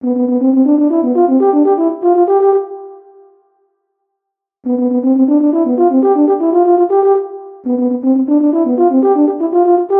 orchestra_tuba_10s.mp3